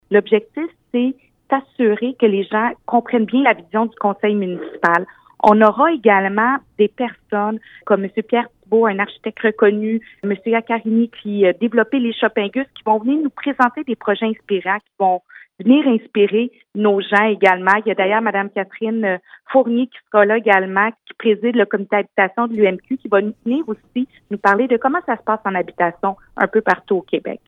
Julie Bourdon, mairesse de Granby